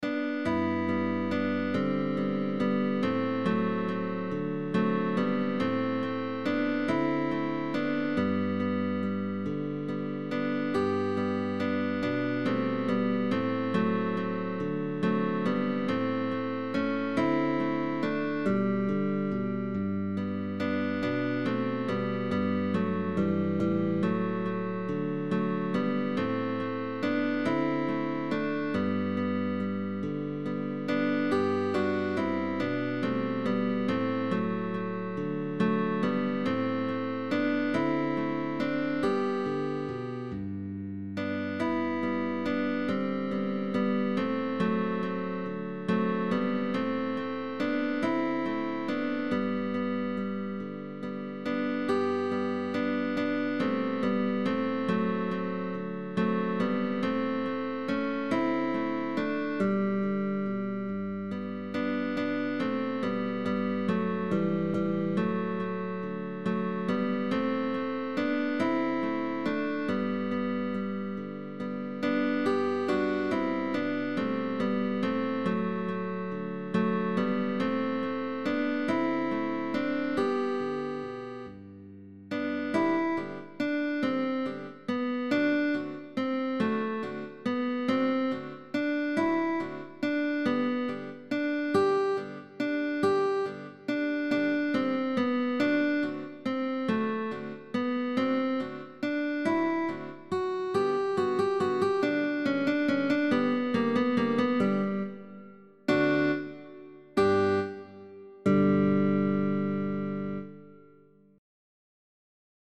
Guitar trio sheetmusic.
GUITAR TRIO